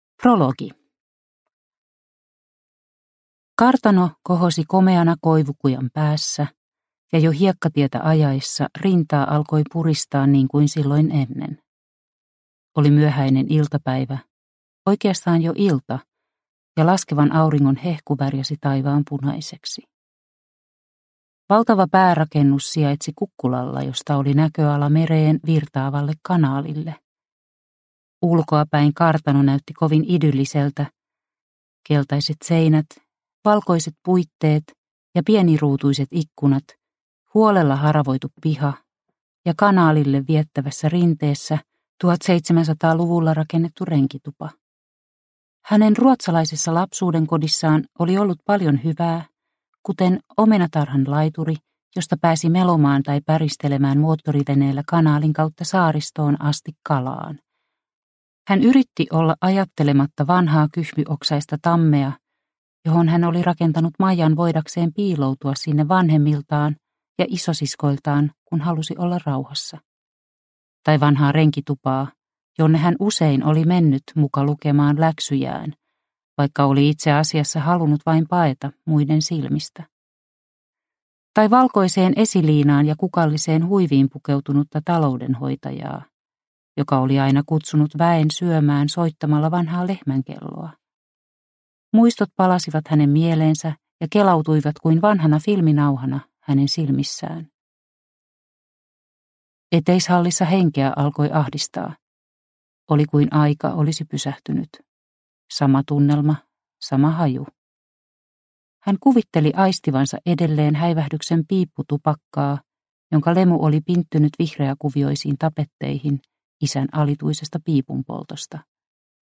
Kun taivas tummuu – Ljudbok – Laddas ner